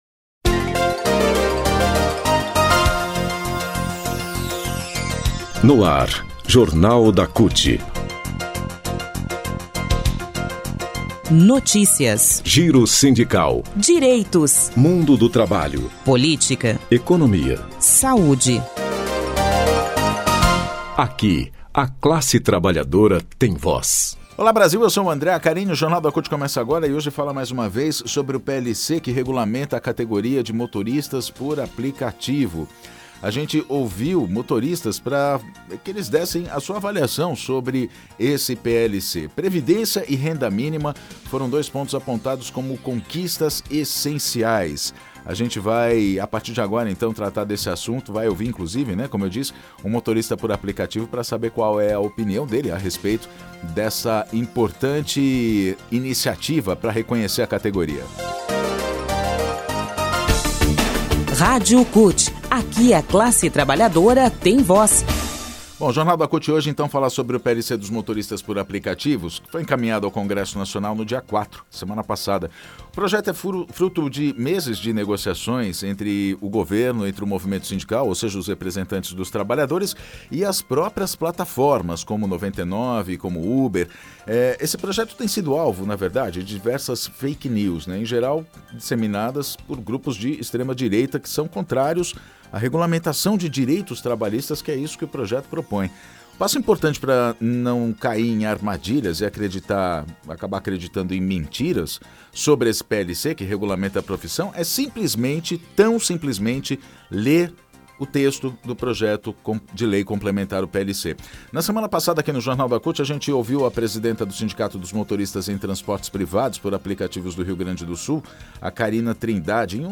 Motoristas por APP falam sobre o PLC que regulamenta categoria Jornal da CUT ouviu trabalhadores